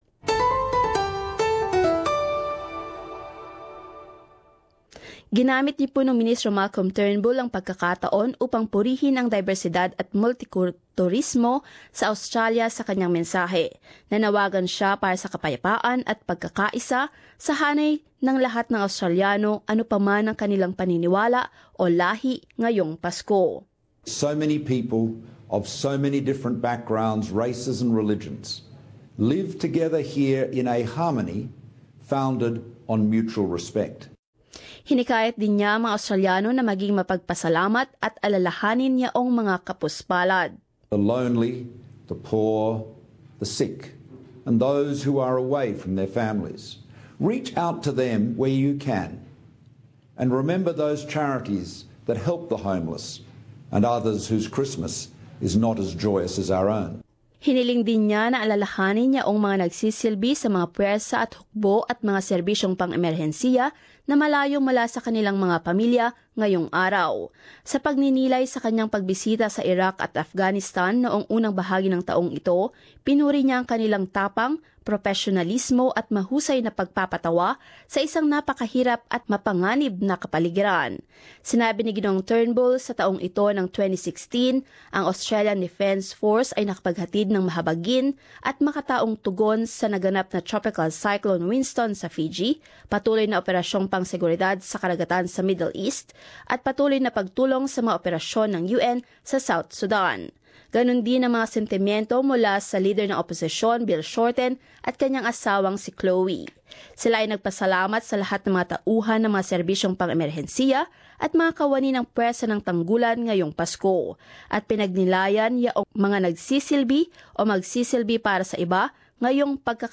Prime Minister Malcolm Turnbull and opposition leader Bill Shorten have delivered their annual Christmas addresses.